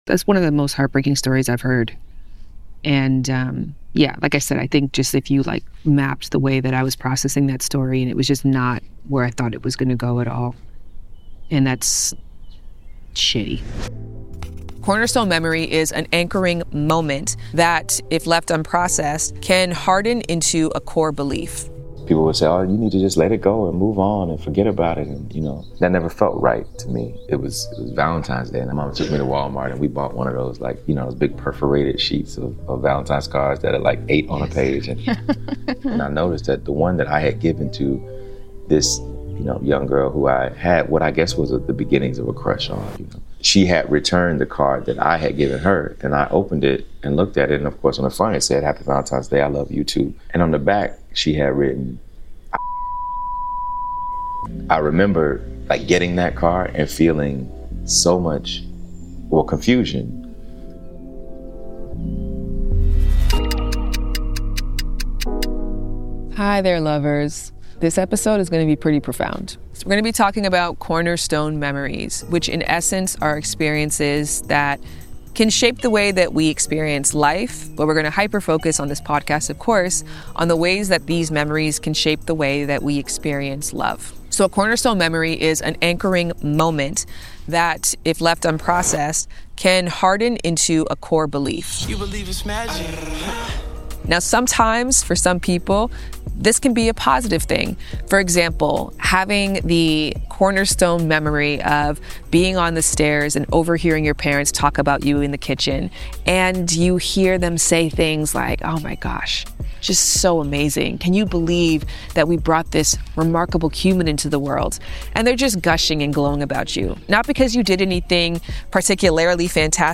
Ft Andre Holland Play episode October 8 46 mins Bookmarks View Transcript Episode Description Trigger Warning: This conversation includes a deeply personal story shared by André Holland, including a moment when someone he had affections for used a racial slur toward him.